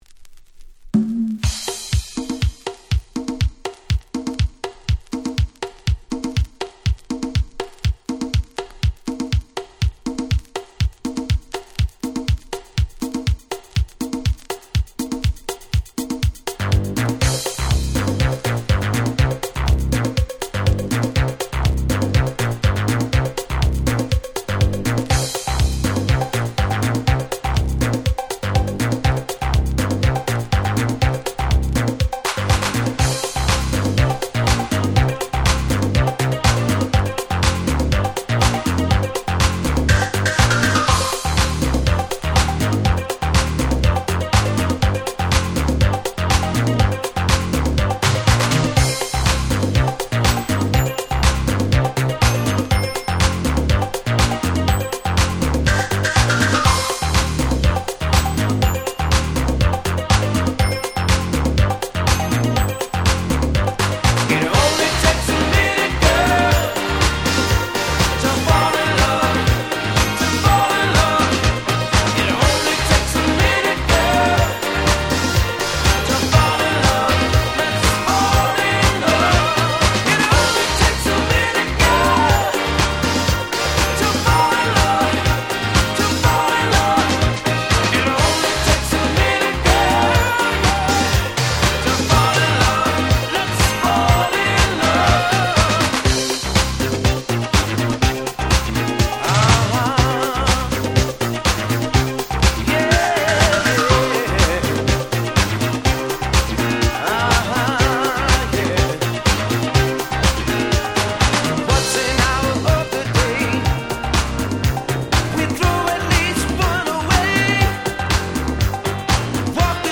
86' Very Nice Disco !!
原曲の良さを完璧に保ちつつさらにフロア使用に底上げされた最強のRemixです！！
ディスコ Dance Classics